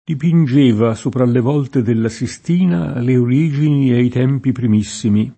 dipinJ%va S1pra lle v0lte della SiSt&na le or&Jini e i t$mpi prim&SSimi] (Cicognani)